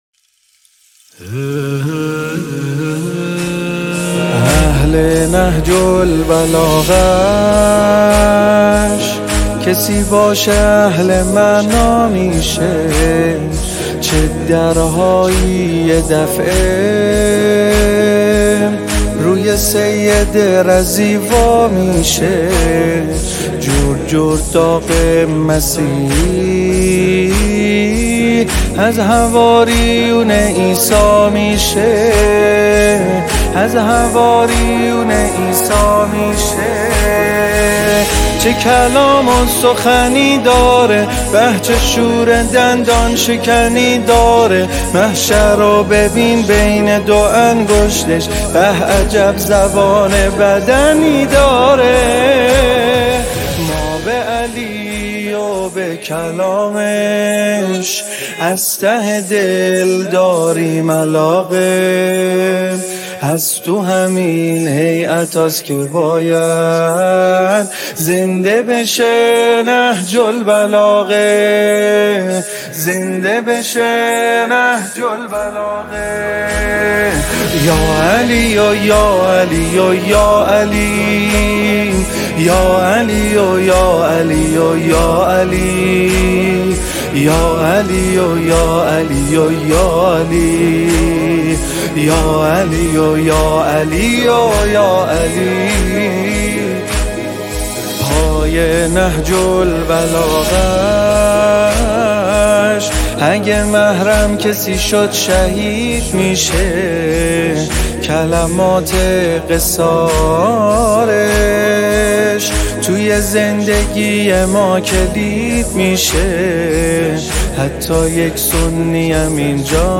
سرود نهج البلاغه